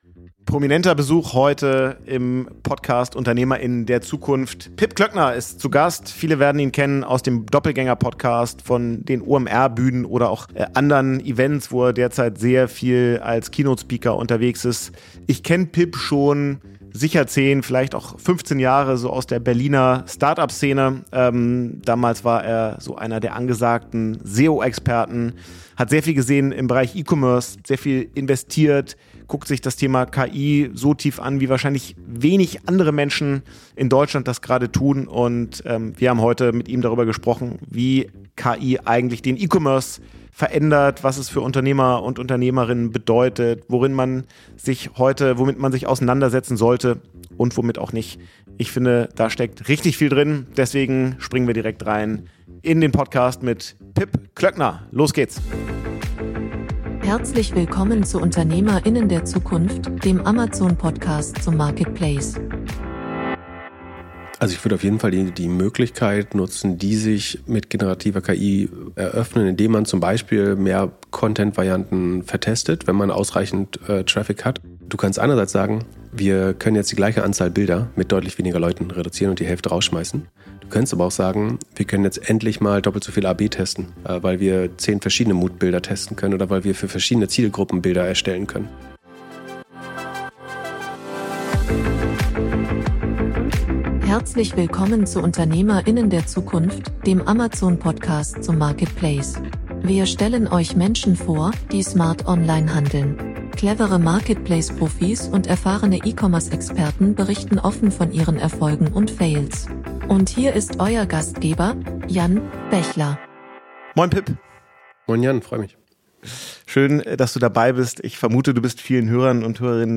Beschreibung vor 4 Monaten In der aktuellen Folge ist einer der bekanntesten deutschen Tech-Podcaster zu Gast im UdZ Podcast